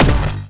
poly_explosion_shockwave.wav